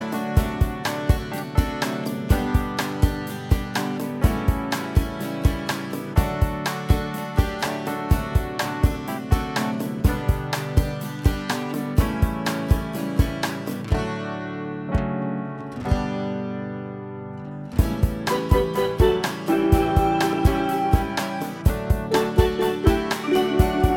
Minus Bass Guitar Country (Male) 4:11 Buy £1.50